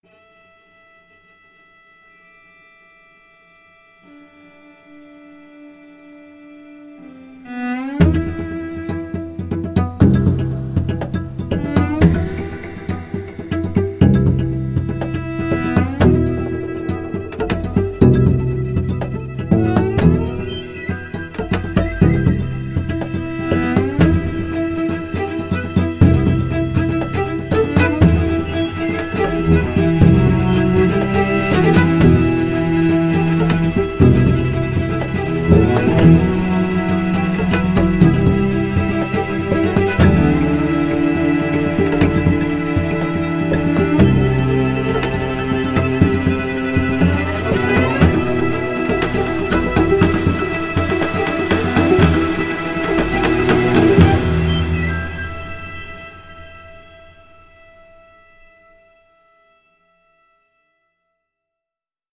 它提供极佳的演奏性、超逼真的音色以及丰富的特效和创意应用，必将让每一位音乐家、电影作曲家和音乐制作人欣喜若狂！
它包含海量的演奏技巧，从经典的连奏、各种拨奏和断奏、颤音，到不寻常的演奏技巧、扩展技巧和电影质感，应有尽有